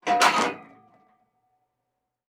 Metal_20.wav